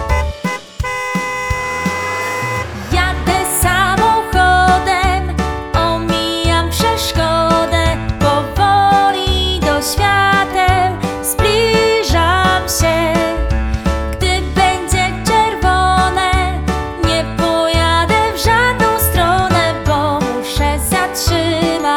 utwór w wersji wokalnej i instrumentalnej